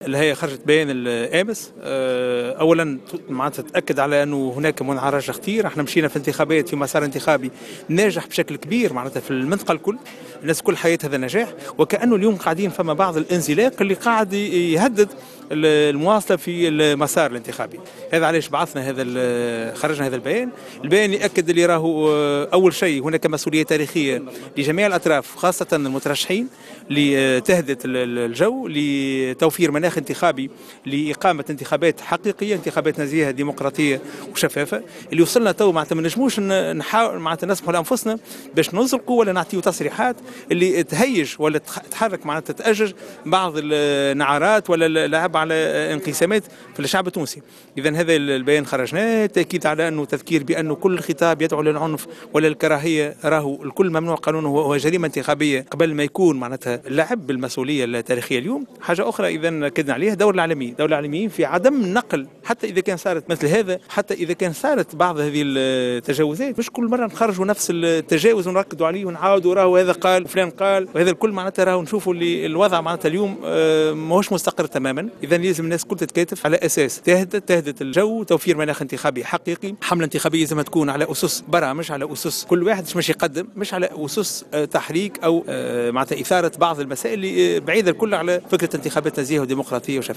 Dans une déclaration accordée à Jawhara FAM, le président de l’ISIE Chafik Sarsar a appelé les candidats à être responsables et à éviter l’appel à la répartition du peuple.